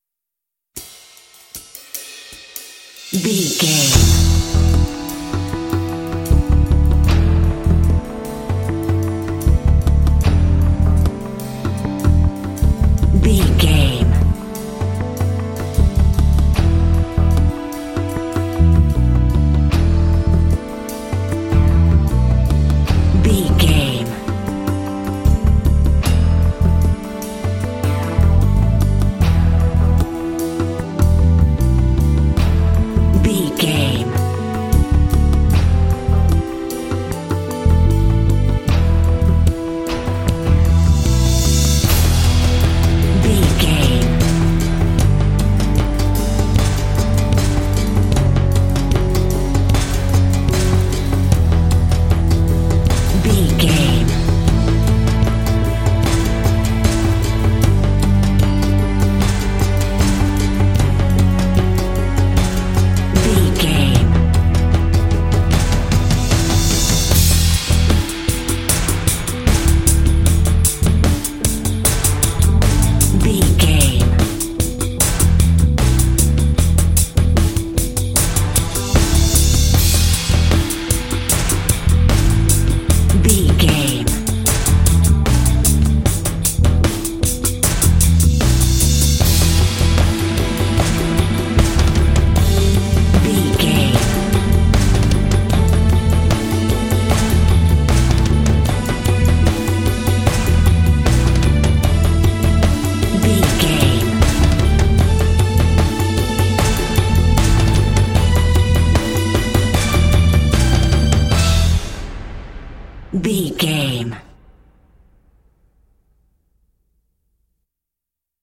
Epic / Action
Fast paced
Aeolian/Minor
dramatic
foreboding
suspense
strings
drums
bass guitar
orchestral
film score
symphonic rock